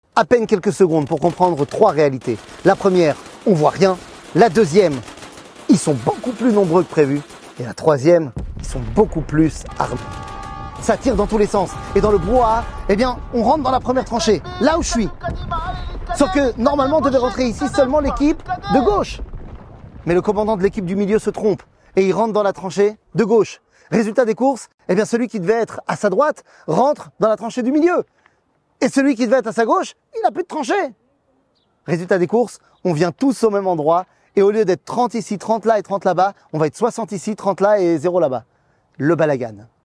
קטגוריה La dur réalité 00:00:40 La dur réalité שיעור מ 21 יוני 2023 00MIN הורדה בקובץ אודיו MP3 (623.81 Ko) הורדה בקובץ וידאו MP4 (4.02 Mo) TAGS : שיעורים קצרים ראה גם רוצים לתרום לעמותה של הרב ?